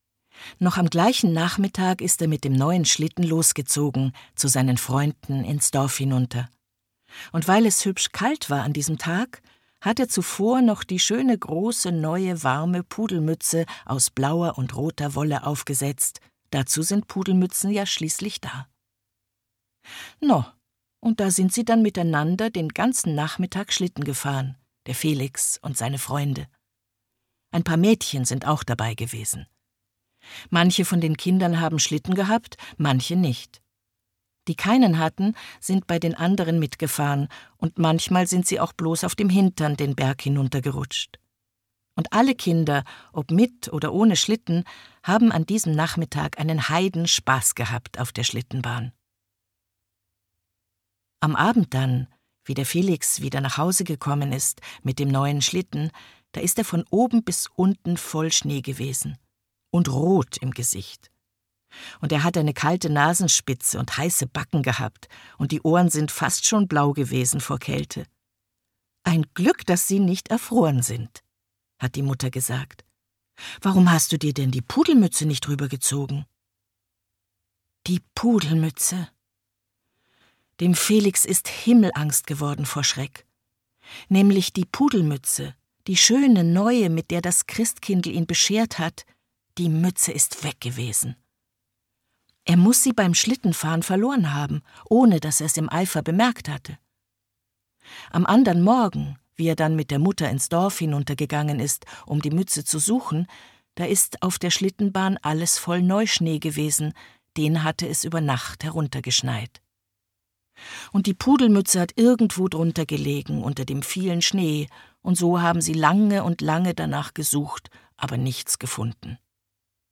Der Engel mit der Pudelmütze - Otfried Preußler - Hörbuch